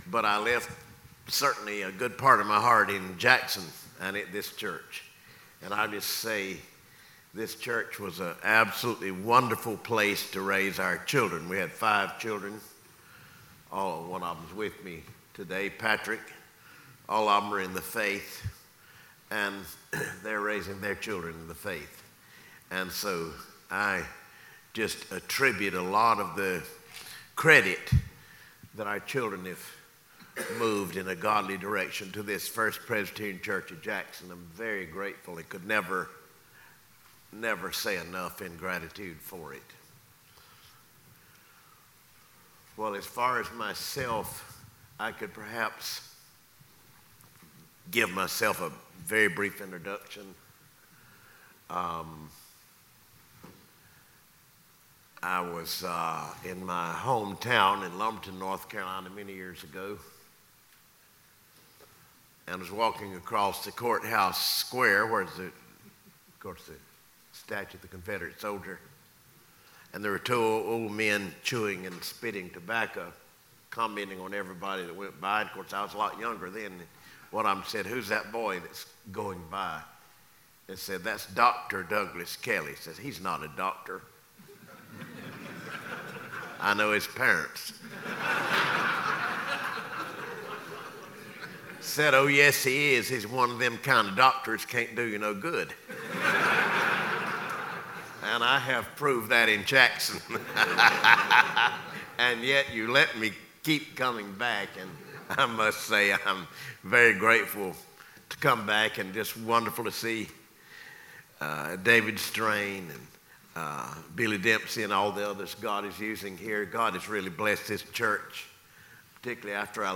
The Incarnation: Men’s Christmas Lunch
Mens-Christmas-Luncheon-2022.mp3